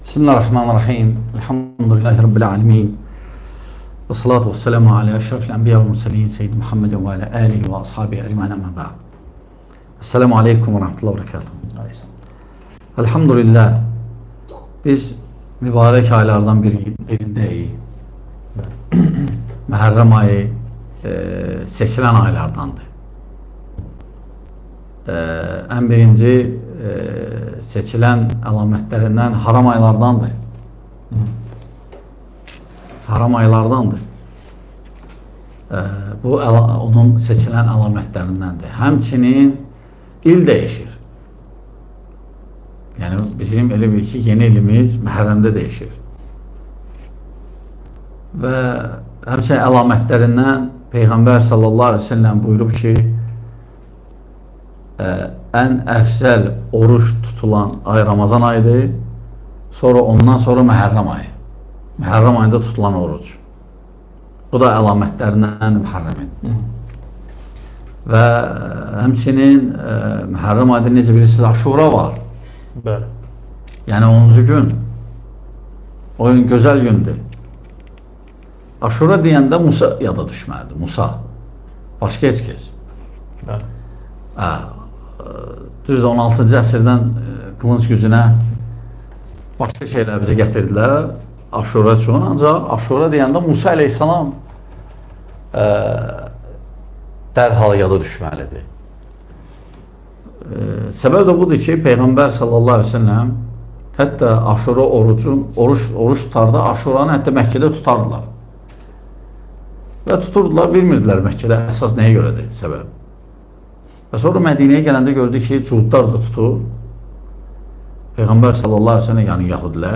İslam təqvimi və bayramları (DƏRSLƏR